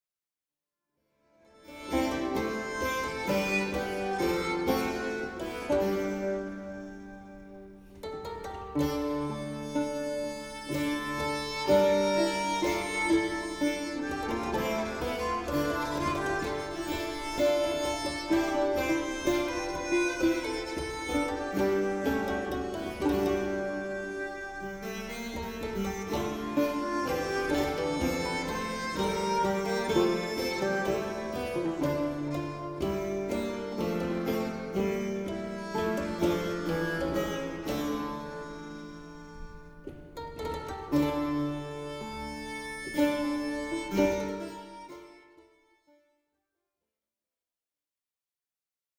Polyphonies courtoises
harpe, vièle, luth, rebec et clavicythérium